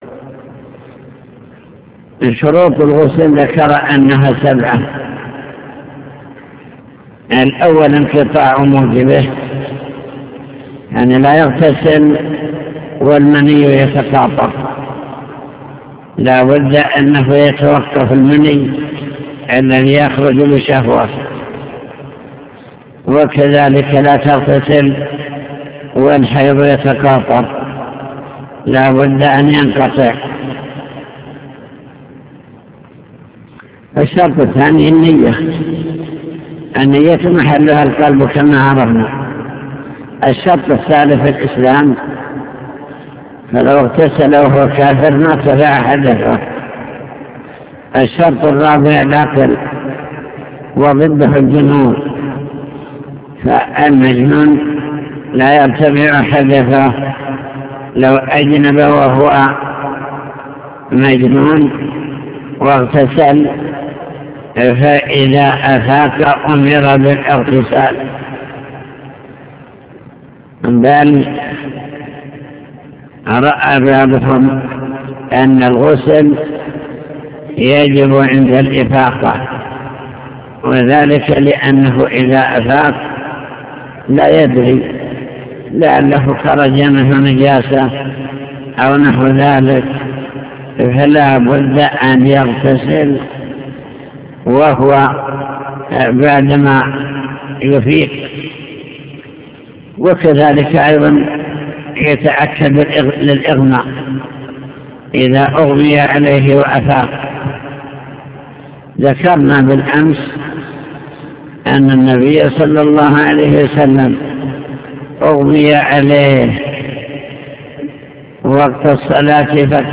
المكتبة الصوتية  تسجيلات - كتب  شرح كتاب دليل الطالب لنيل المطالب كتاب الطهارة باب الغسل